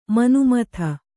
♪ manumatha